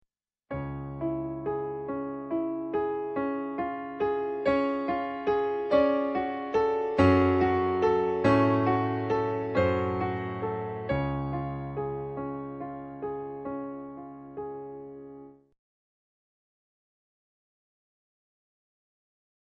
Mon piano : yamaha P140, C. Bechstein B210
je ne l'ai jamais jouée mais voilà tes 3 mesures avec mes petits doigts et mon yam